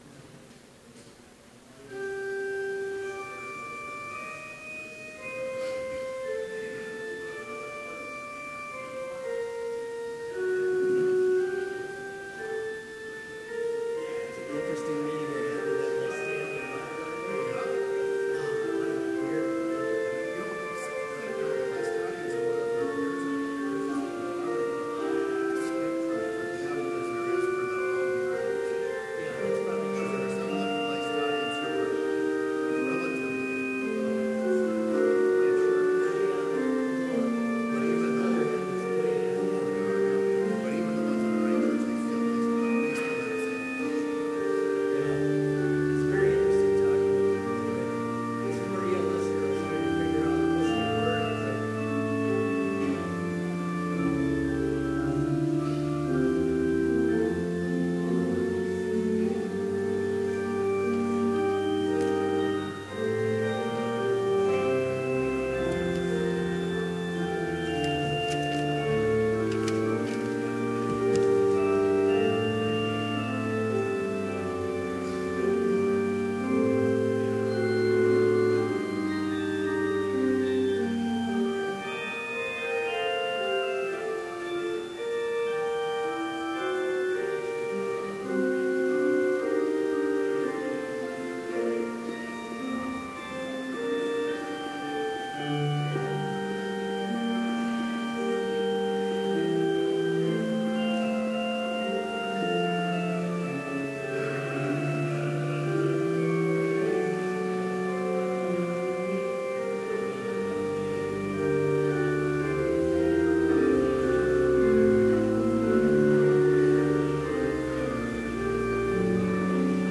Complete service audio for Chapel - January 31, 2017